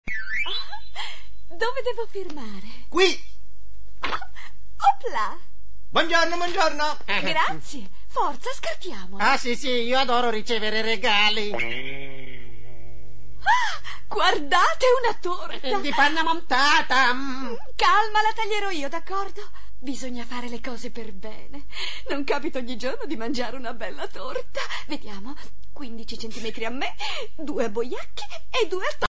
nel cartone animato "Yattaman", in cui doppia Miss Dronio.